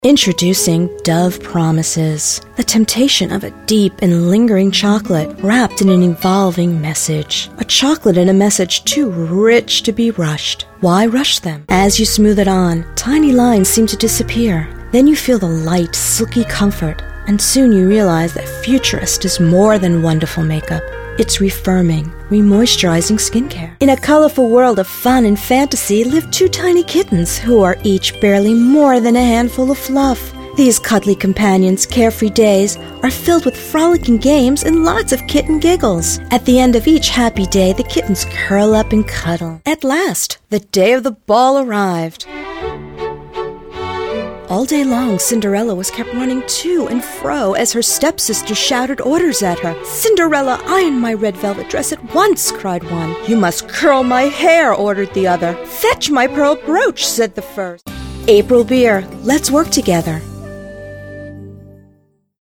Female Voice-Over Talent
Compilation Demo:
NOTE: These files are high quality stereo audio files, therefore they are large in size.